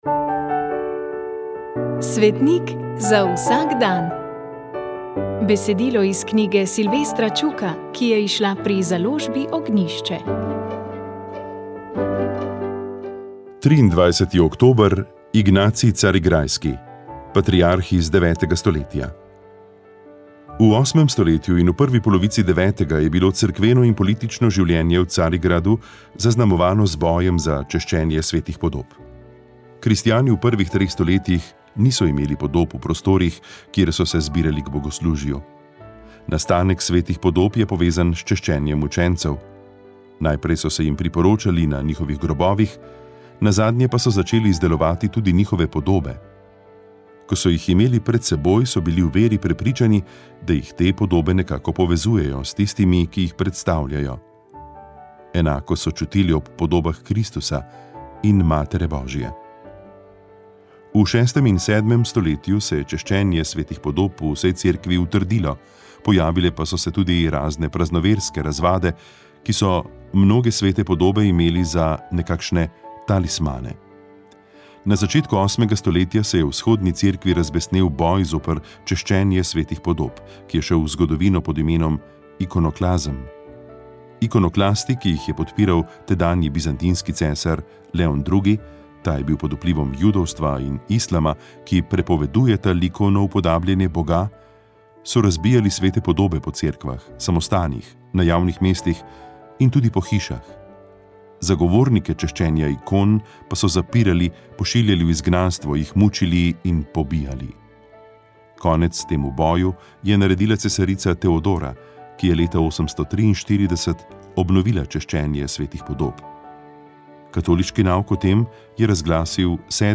Imeli smo pravi moški pogovor. Ne samo zaradi petih moških sogovornikov, ampak tudi zaradi teme: spoznavali smo moško duhovnost. Sredi aprila bo namreč na Sladki Gori že četrto vseslovensko srečanje mož, očetov in sinov.